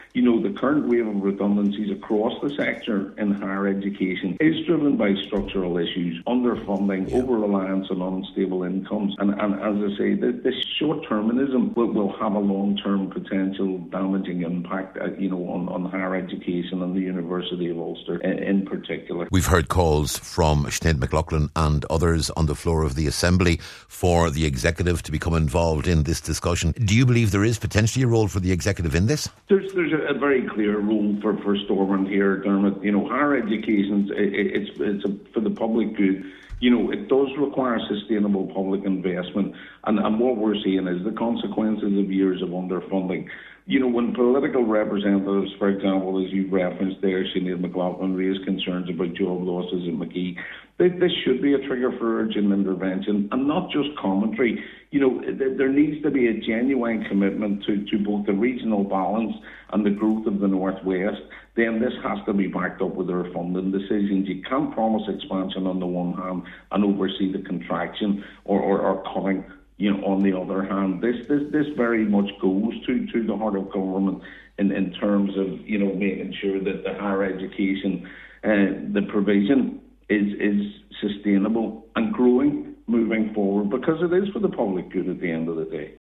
Highland Radio